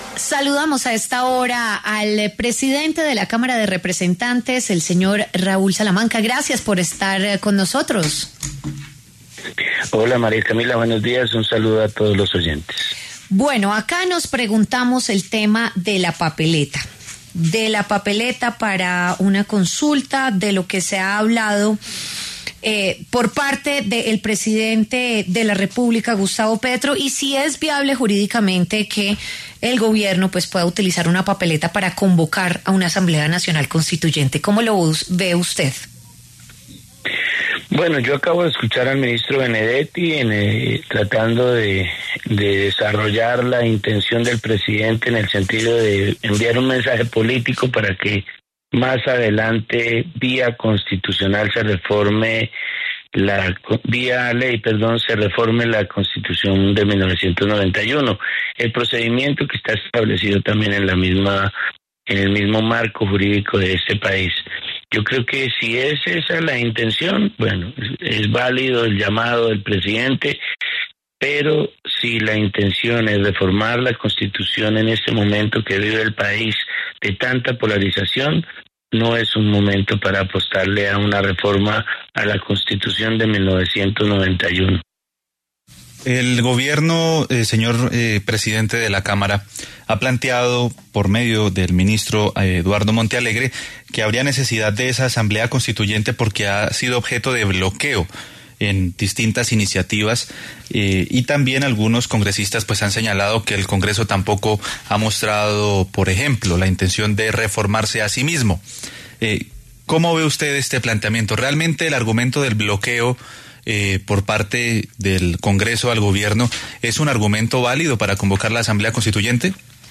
En diálogo con La W, Jaime Raúl Salamanca, presidente de la Cámara de Representantes, se pronunció acerca del anuncio del presidente Gustavo Petro de que, en las elecciones las legislativas de marzo de 2026, “será entregada una papeleta para convocar la asamblea nacional constituyente”.